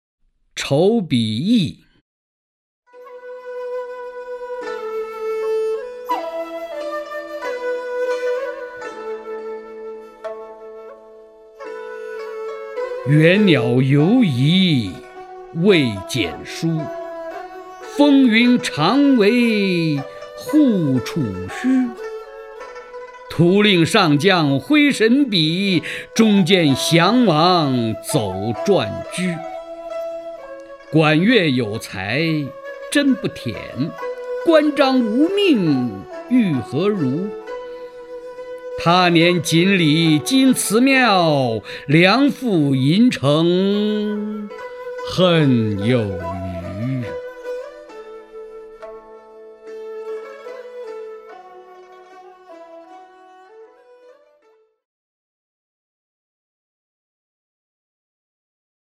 首页 视听 名家朗诵欣赏 曹灿
曹灿朗诵：《筹笔驿》(（唐）李商隐)